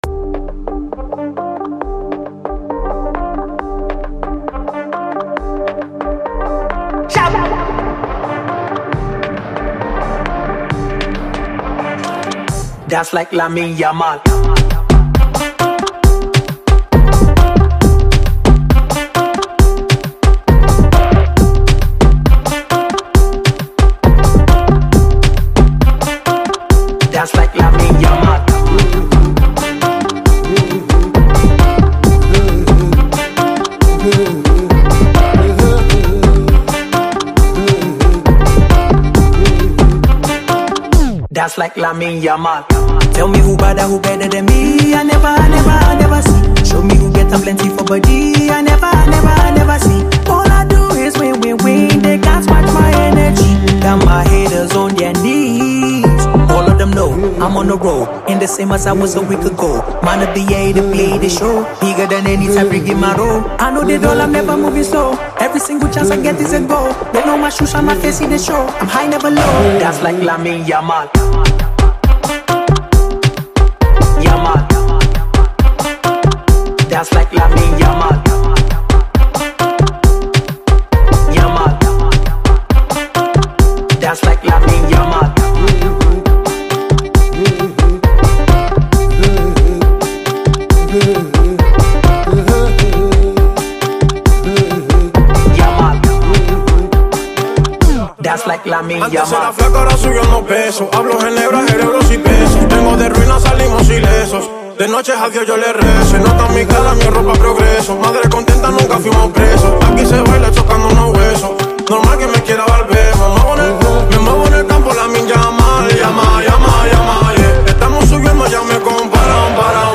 and afrobeat artist